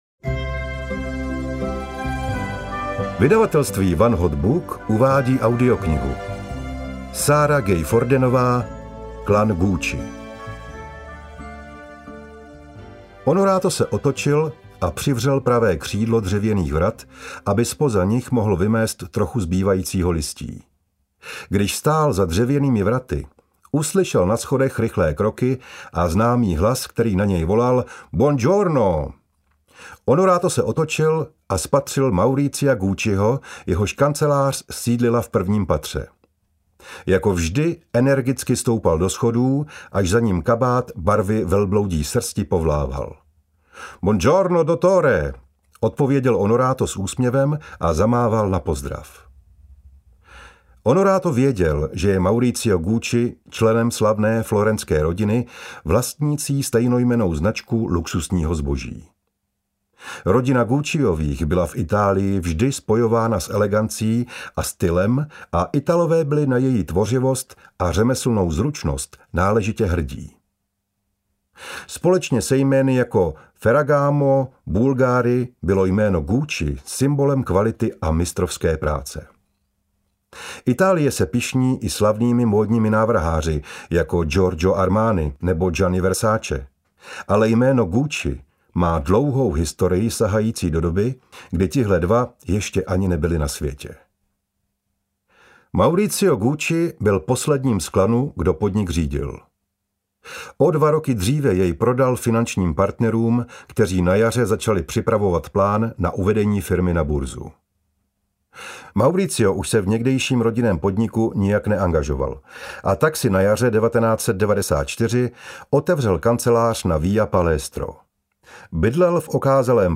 Klan Gucci audiokniha
Ukázka z knihy